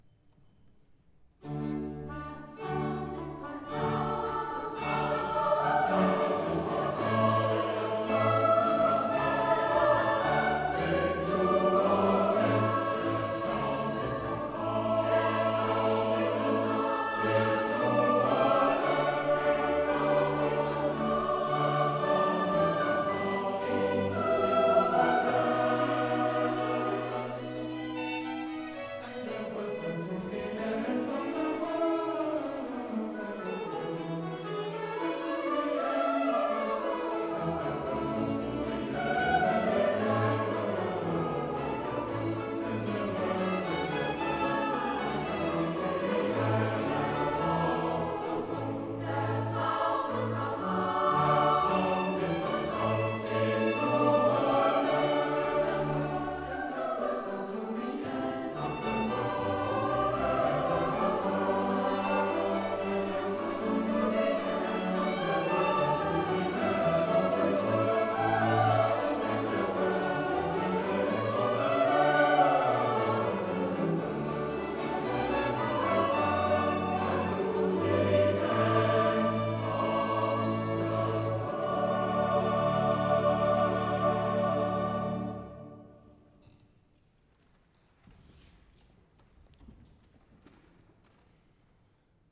Konzerte am 26. April in der Kreuzkirche Ottensen und am 27. April in der Liebfrauenkirche Fischerhude
Live-Mitschnitte: